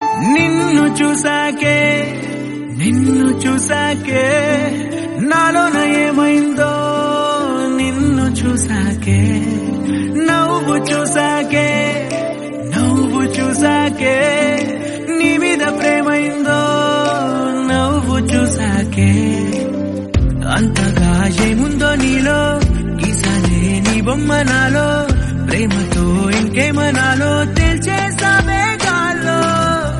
melodious
best flute ringtone download | love song ringtone